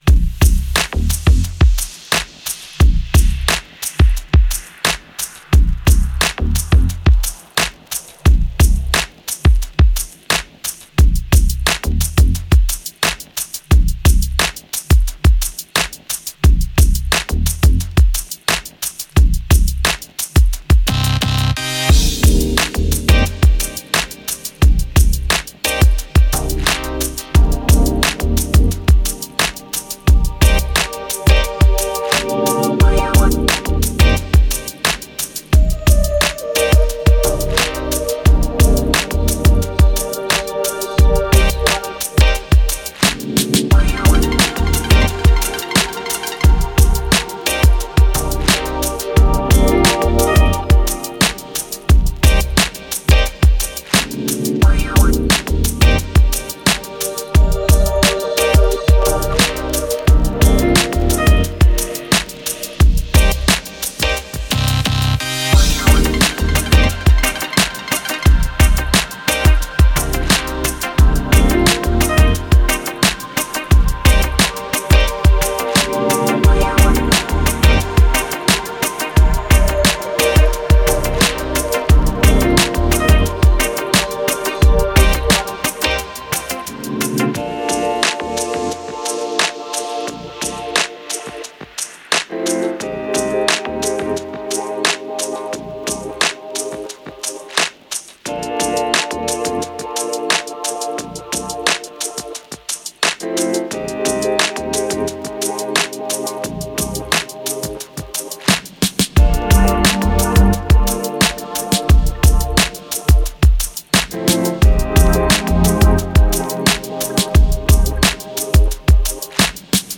Genre: chillout, downtempo.